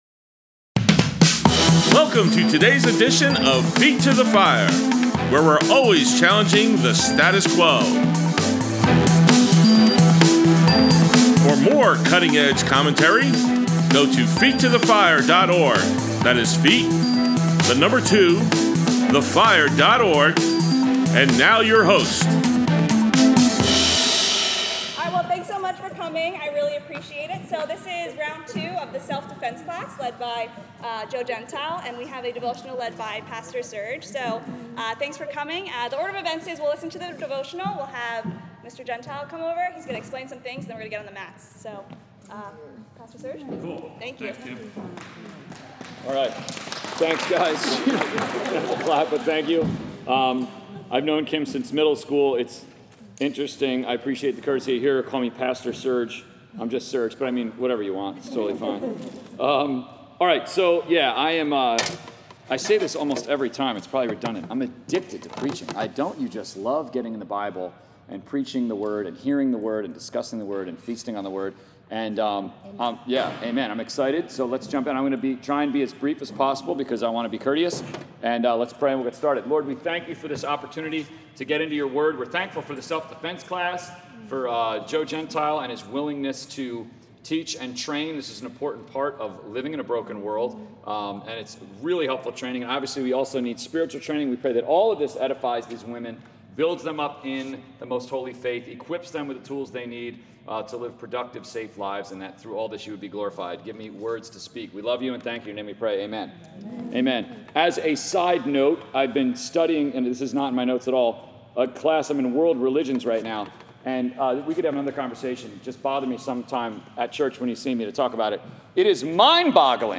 Saturday Sermons 8.6.22 A Biblical View of Femininity Part 2, Selected Scriptures